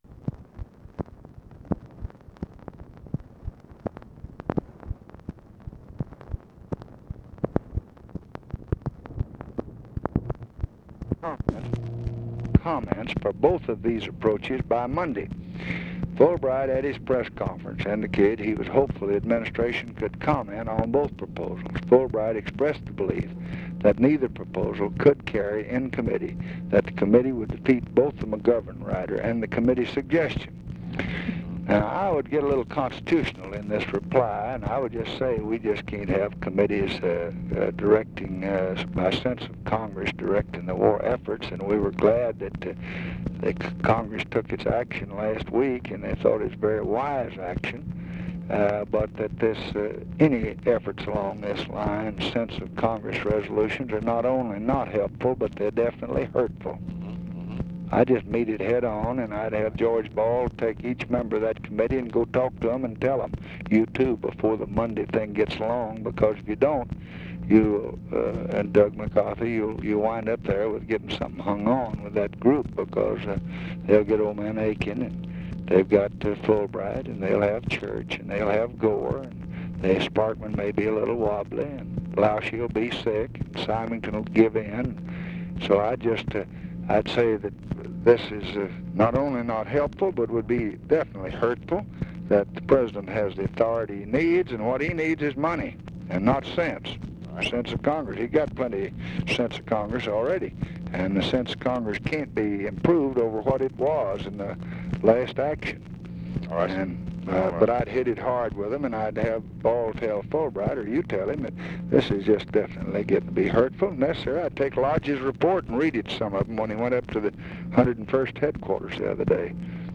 Conversation with DEAN RUSK, March 4, 1966
Secret White House Tapes